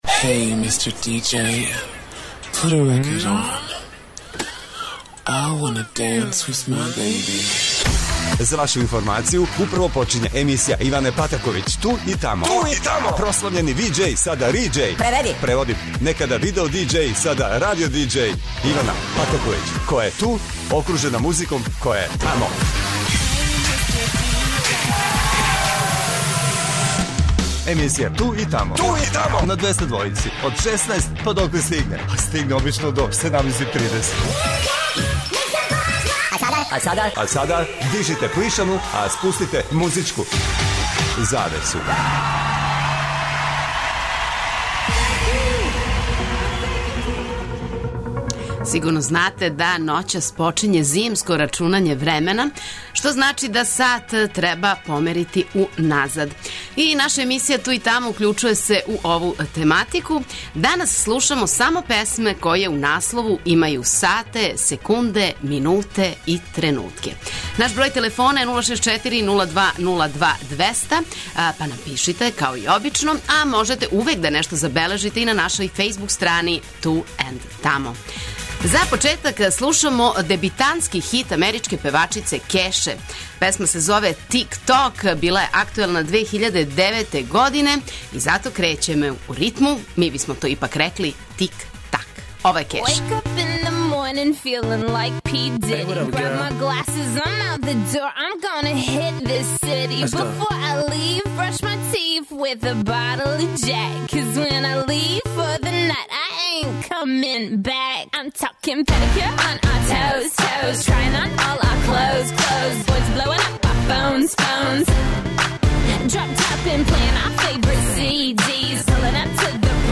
Овог викенда прелазимо на зимско рачунање времена па у емисији 'Ту и тамо' слушамо хитове који у свом наслову имају сате, секунде, минуте и тренутке.
Очекују вас велики хитови, страни и домаћи, стари и нови, супер сарадње, песме из филмова, дуети и још много тога.